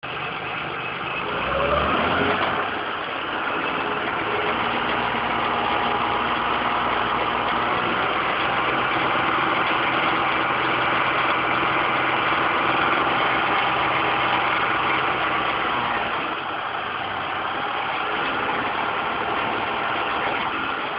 J'ai fait deux vidéos au téléphone portable (qualité minable mais bon...).
En donnant un peu de régime : je n'étais pas très près car mes bras ne sont pas extensibles... mais là, c'est le drame... j'entends des "crac crac cracarac" (on les entend en tendant bien l'oreille) comme si un morceau de je sais pas quoi se baladait ou tapait, enfin je sais pas...